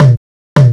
PERC LOOP7-L.wav